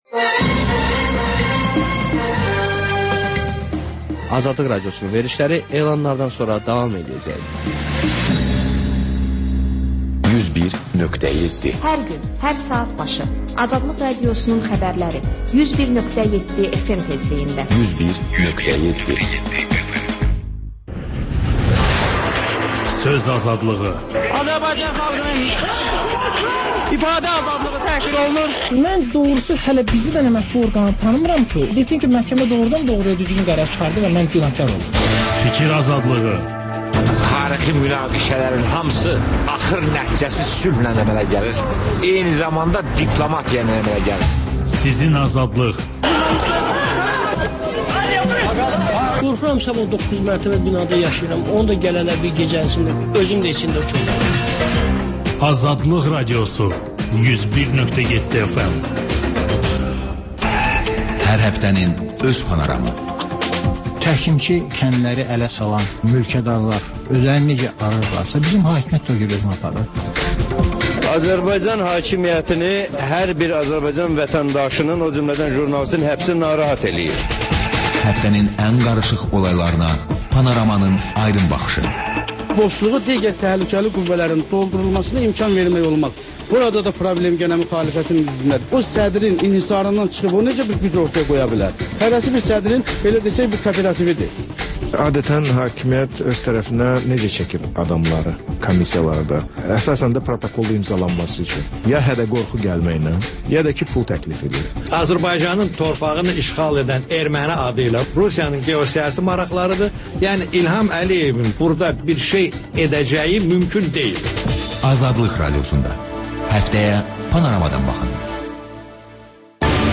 Xəbərlər, XÜSUSİ REPORTAJ: Ölkənin ictimai-siyasi həyatına dair müxbir araşdırmaları və TANINMIŞLAR rubrikası: Ölkənin tanınmış simaları ilə söhbət